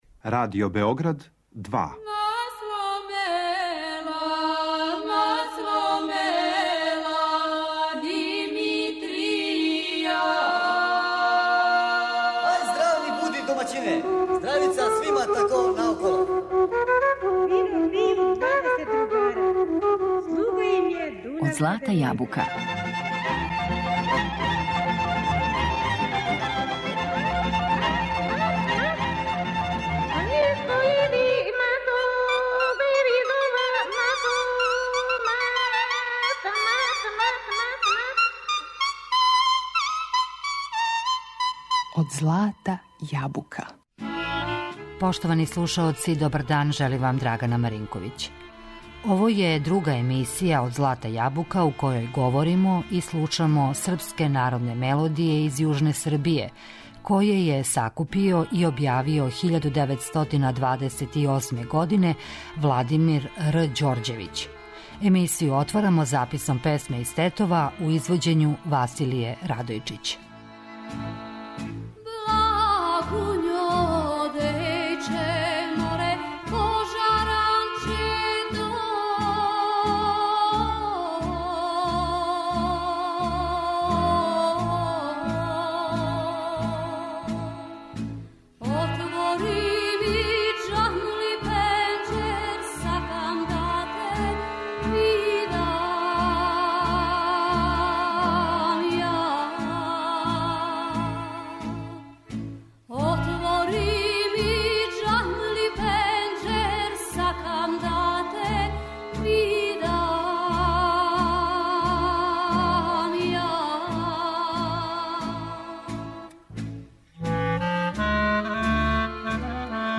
Емисија изворне народне музике